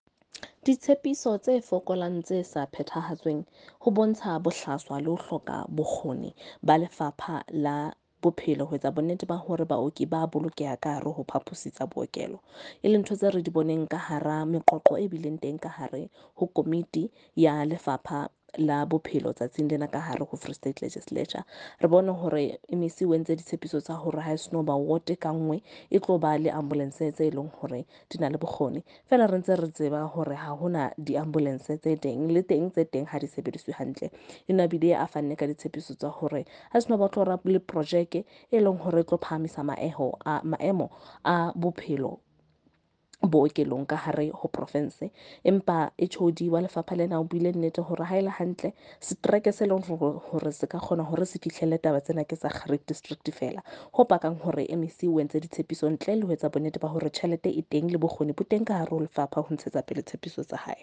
Sesotho by Karabo Khakhau MP.